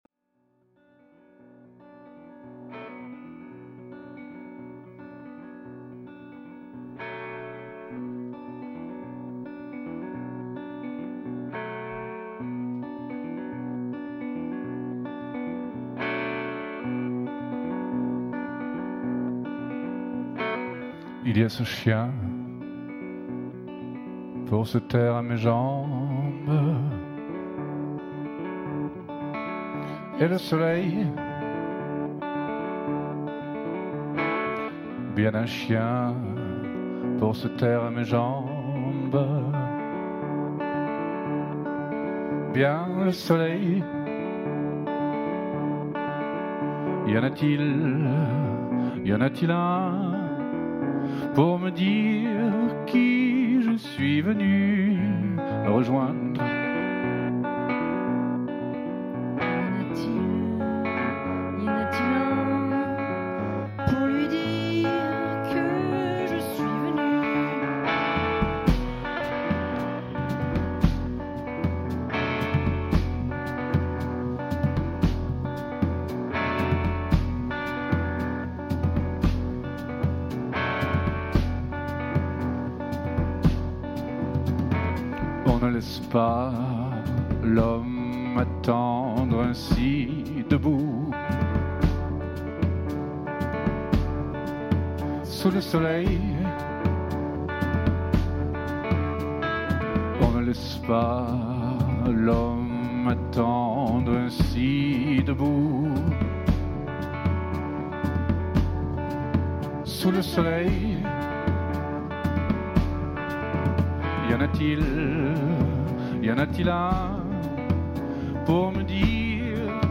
an intimate concert